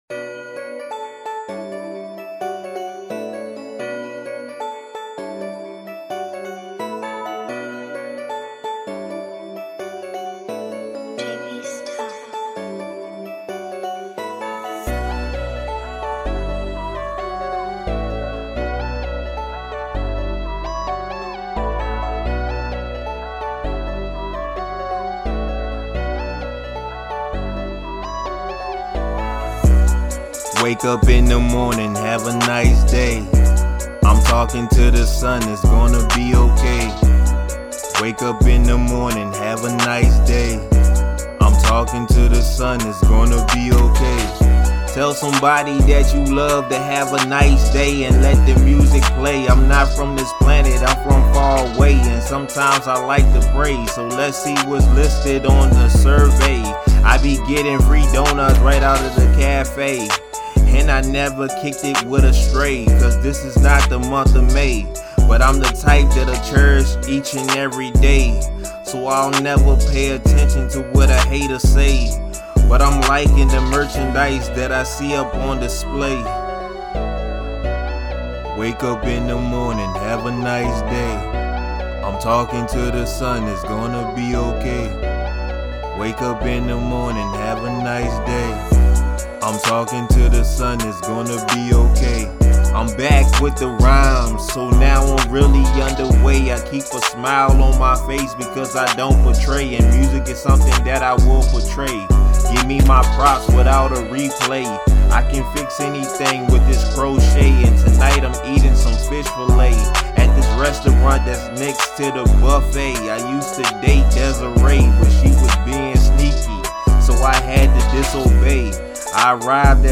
Rap
it's definitely a chill vibe